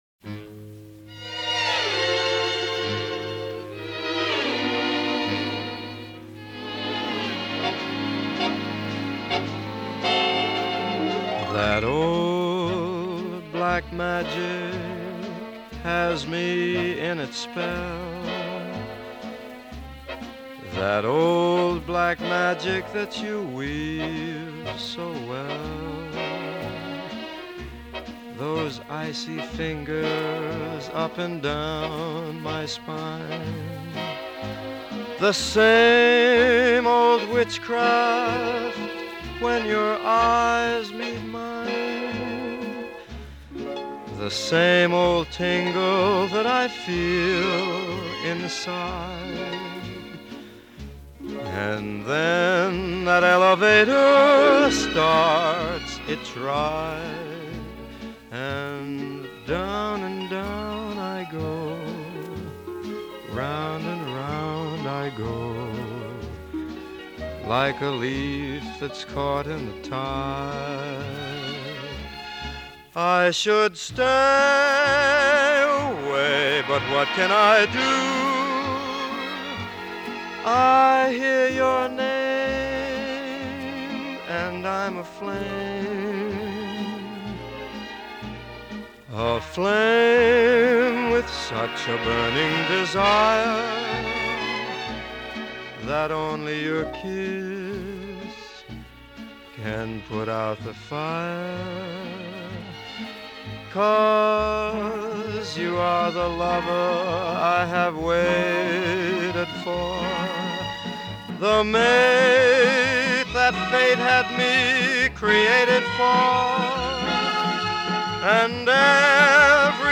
1942   Genre: Pop   Artist